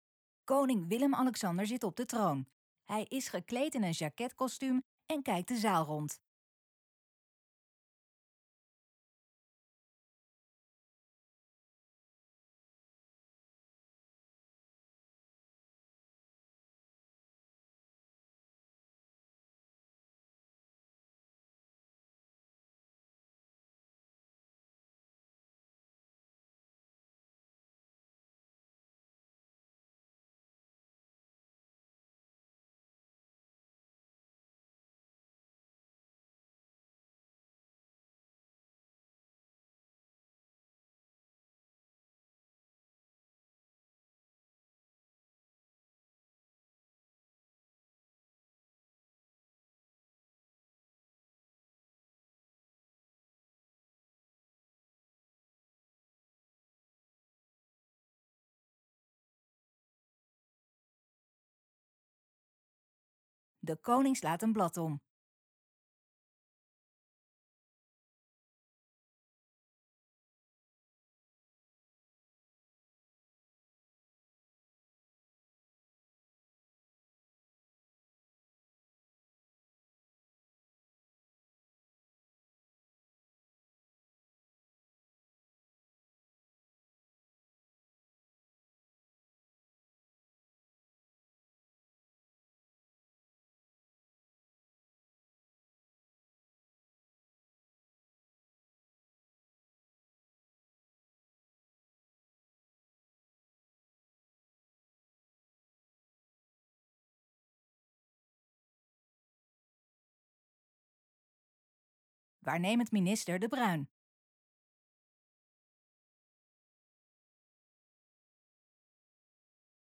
Koning Willem-Alexander opent het nieuwe werkjaar van het parlement in de Grote Kerk in Den Haag met het uitspreken van de Troonrede.